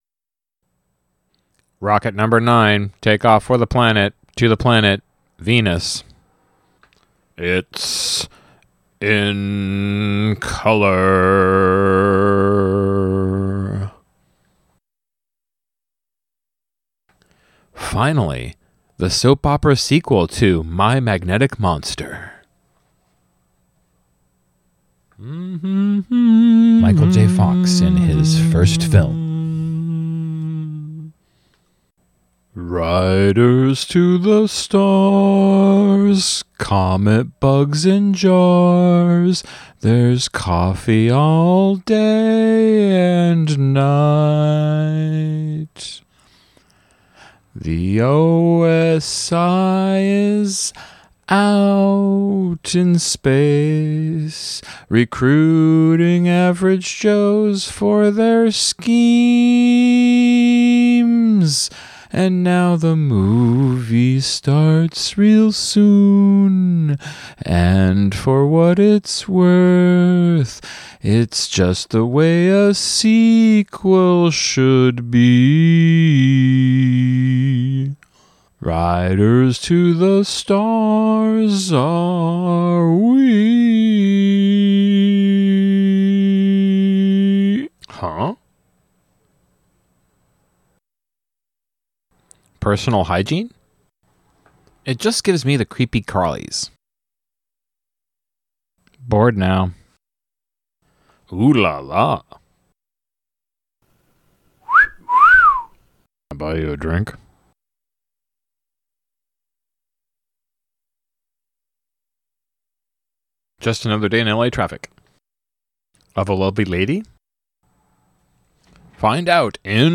riders-to-the-stars-mid-valley-mutations-commentary.mp3